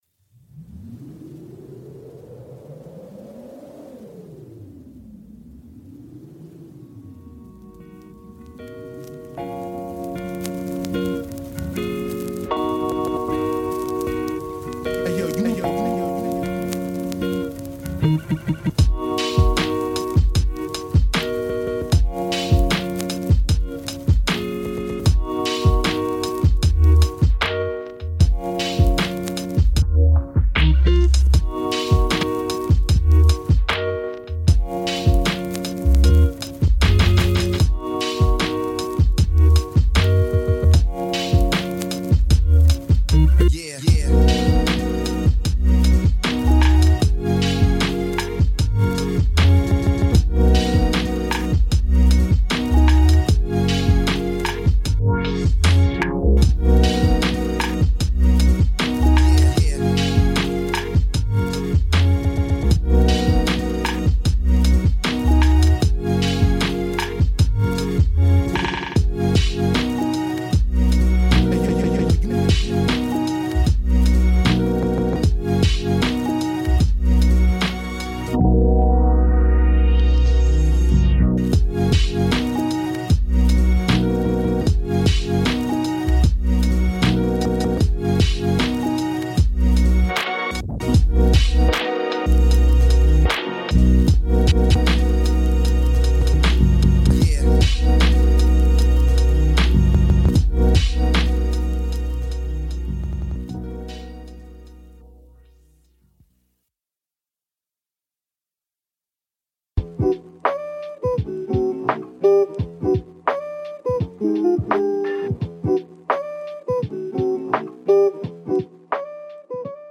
Mozart : Focus Mental Piano